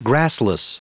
Prononciation du mot grassless en anglais (fichier audio)
Prononciation du mot : grassless